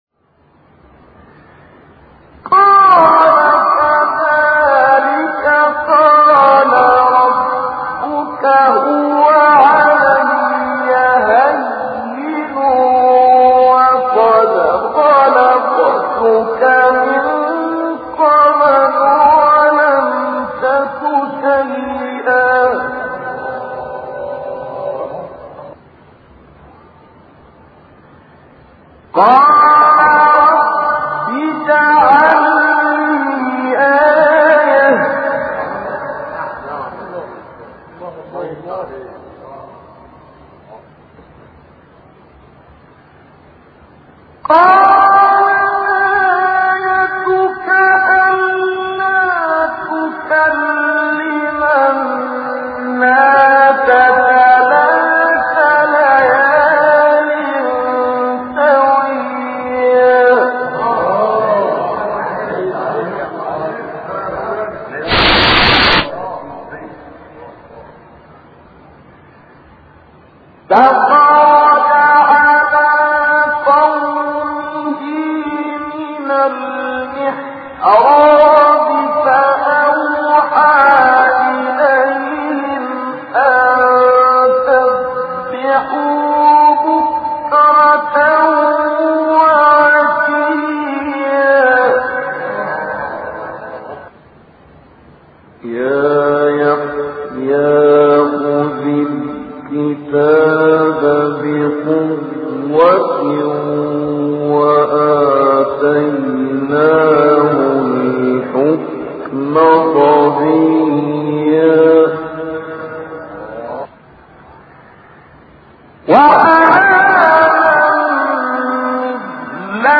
مقام : مرکب خوانی(ترکیب بیات و صبا * رست * چهارگاه * رست * سه گاه * چهارگاه * بیات)